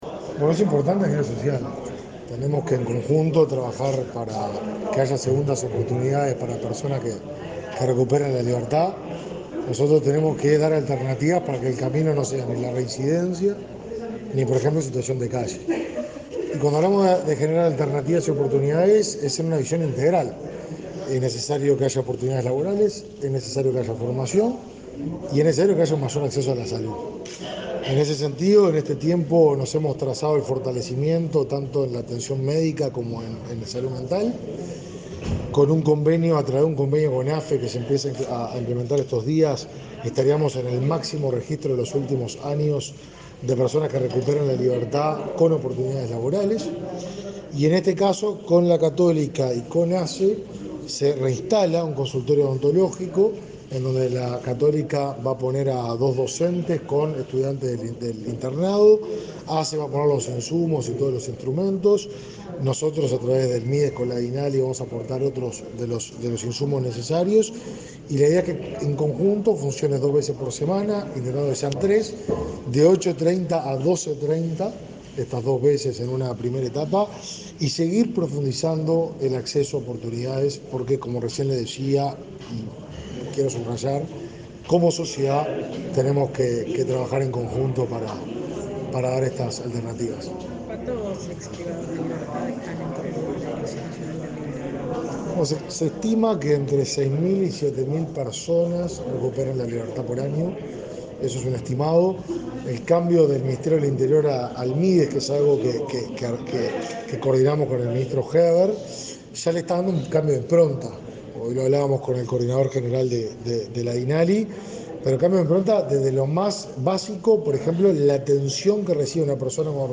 Declaraciones del ministro de Desarrollo Social, Martín Lema
Declaraciones del ministro de Desarrollo Social, Martín Lema 20/09/2022 Compartir Facebook X Copiar enlace WhatsApp LinkedIn El ministro de Desarrollo Social, Martín Lema, participó en el acto de reinauguración del consultorio odontológico de la Dirección Nacional del Liberado y luego dialogó con la prensa.